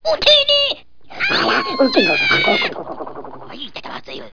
Some excited Jawas